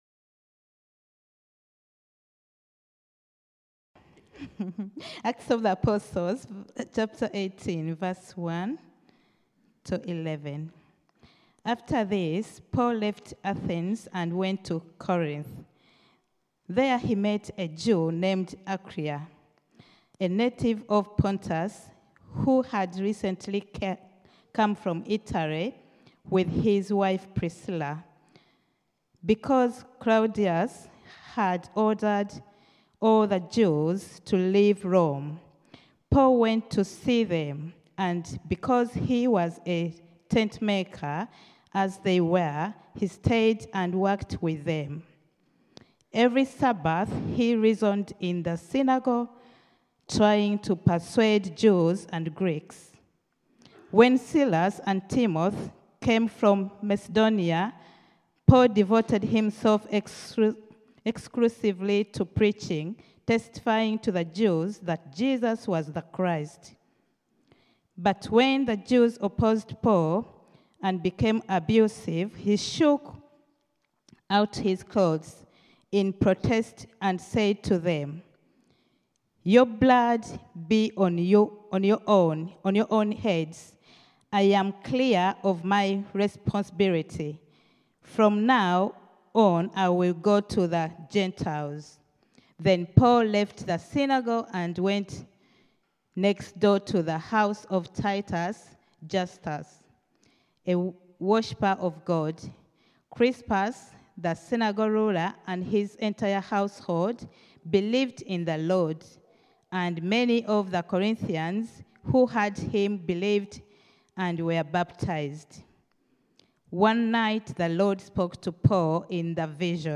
Sermons Spring 2026 - The Mission of Acts